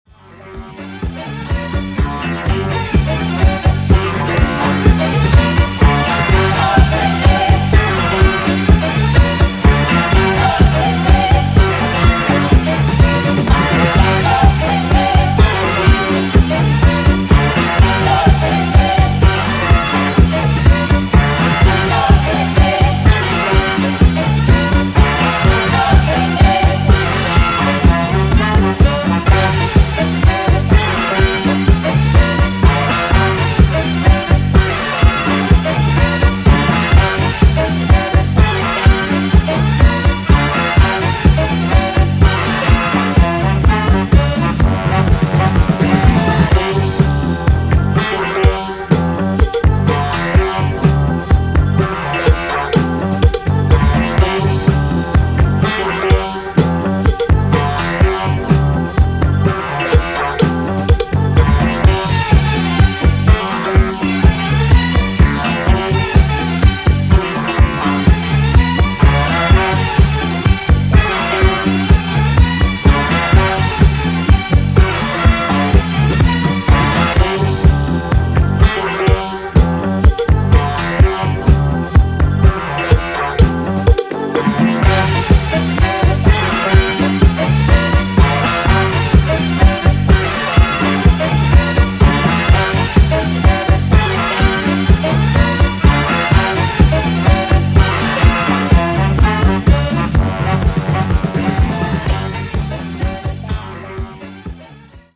今回は、ラテンやジャズファンク要素を持ったご機嫌なブギー・エディットを4曲披露！
ジャンル(スタイル) DISCO / EDITS